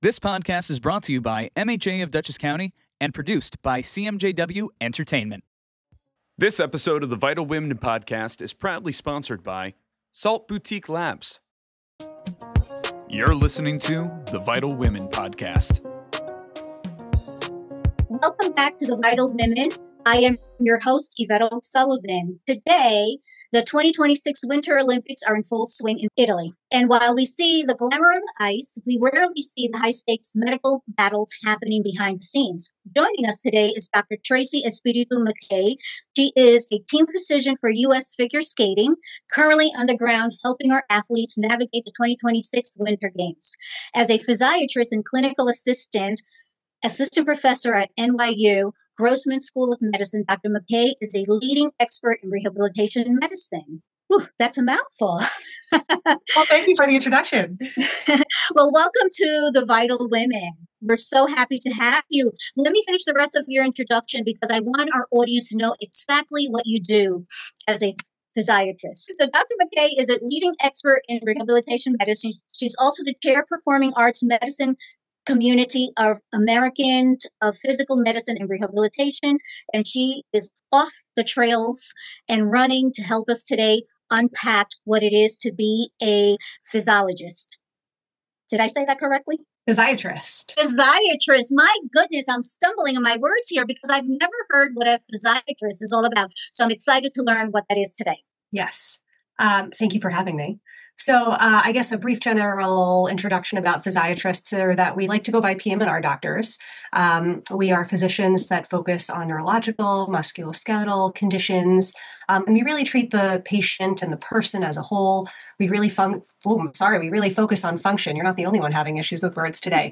Interview of Olympic Proportions